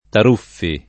[ tar 2 ffi ]